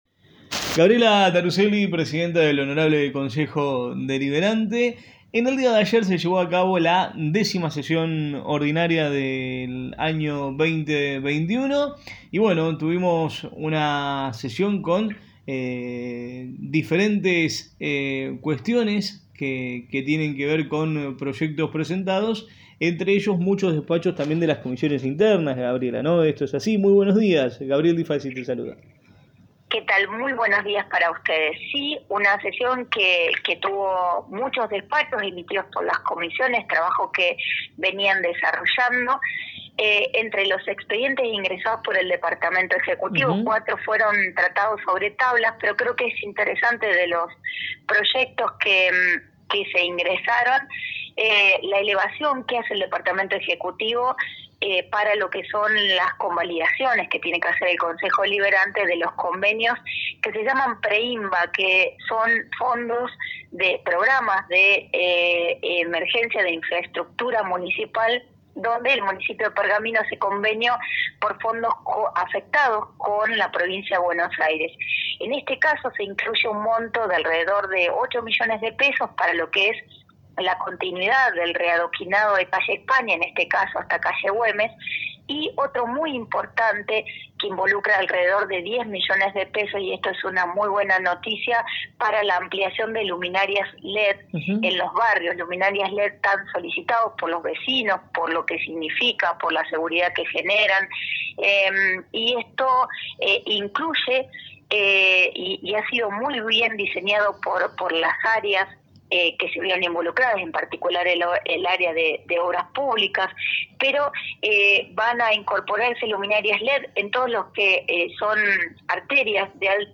Gabriela Taruselli, presidenta del Honorable Concejo Deliberante compartió un resumen de la 10ma Sesión Ordinaria del año.